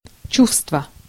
Ääntäminen
Synonyymit feeling affect agitation Ääntäminen CA US GenAm: IPA : /ɪˈmoʊʃən/ IPA : /iˈmoʊ.ʃən/ Canada: IPA : /ɪˈmoʊʃən/ IPA : /iˈmoʊ.ʃən/ RP : IPA : /ɪˈməʊ.ʃən/ Tuntematon aksentti: IPA : /əˈmoʊ.ʃən/ IPA : /ɛˈmoʊ.ʃən/